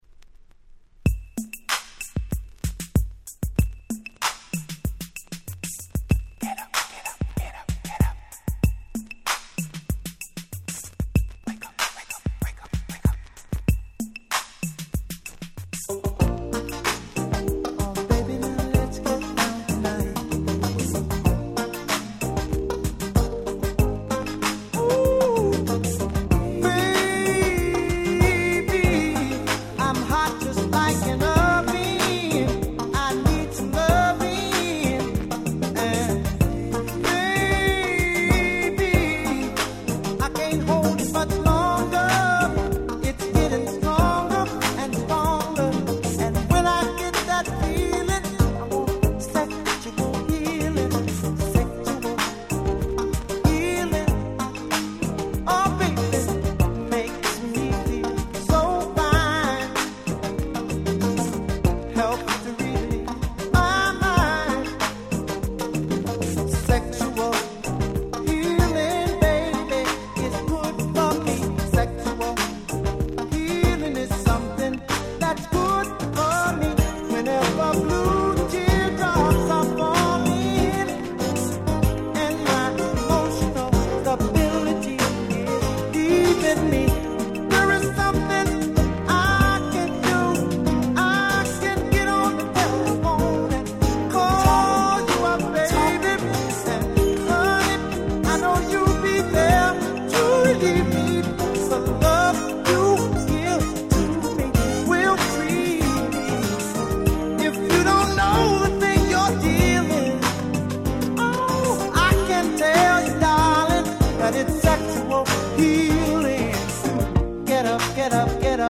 音質もバッチリだし使い易いしで海外でも非常に人気で安定した価格のシリーズです！